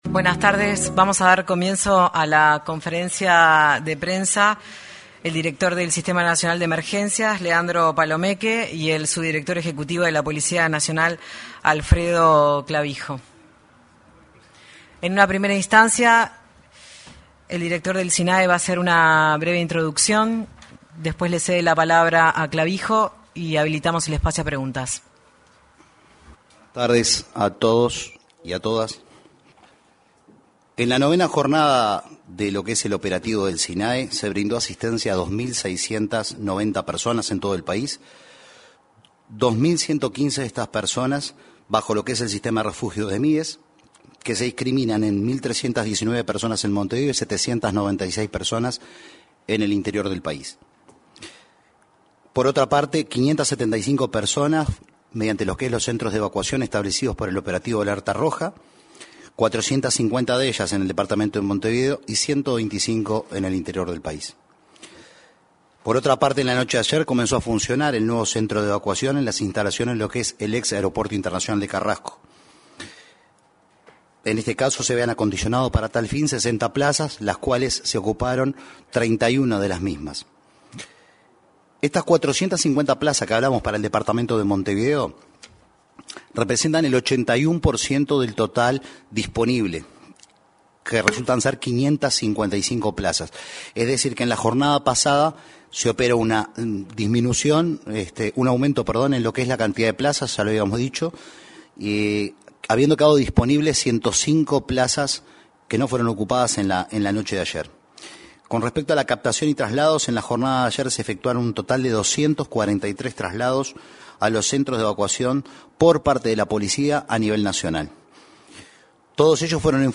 Conferencia de prensa del Sinae
Conferencia de prensa del Sinae 02/07/2025 Compartir Facebook X Copiar enlace WhatsApp LinkedIn El director del Sistema Nacional de Emergencias (Sinae), Leandro Palomeque, y el subdirector de la Policía Nacional, Alfredo Clavijo, informaron, en una conferencia de prensa en la Torre Ejecutiva, sobre las acciones para proteger a personas en situación de calle ante las bajas temperaturas.